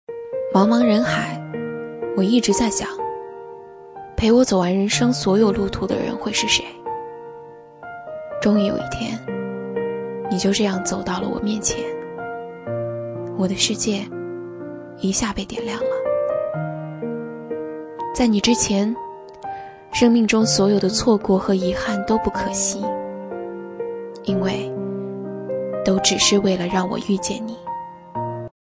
【女58号旁白 独白情感】遇见你自然风